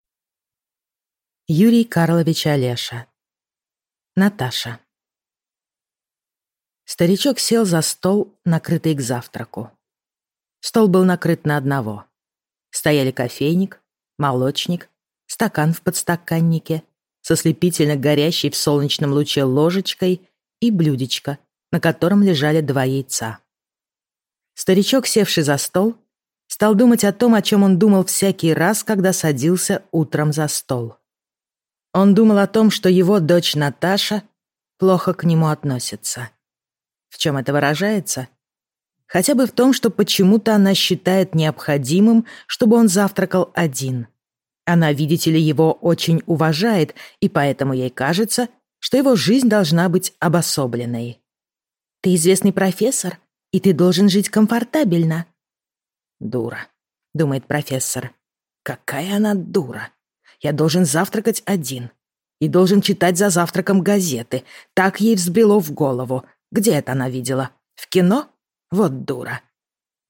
Аудиокнига Наташа | Библиотека аудиокниг